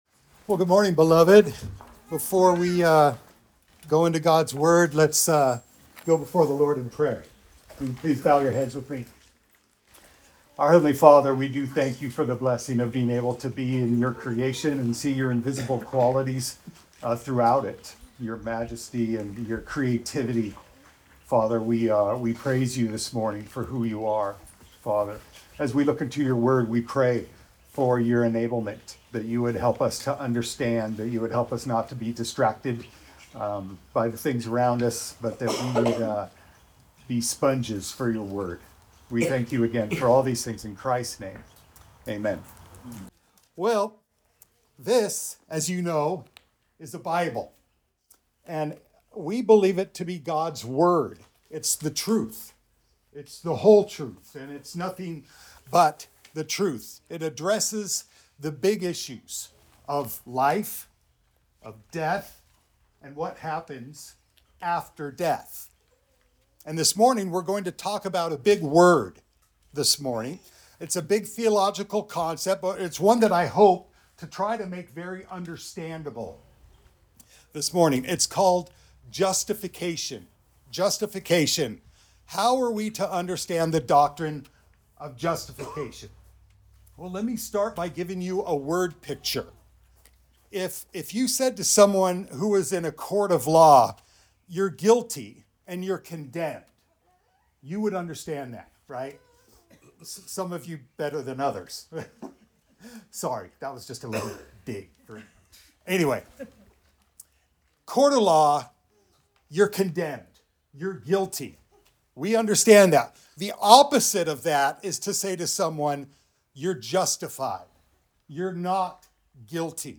Recording taken from the GBF Summer Camp.
Matthew 19:16-22 Service Type: Sunday Service Recording taken from the GBF Summer Camp.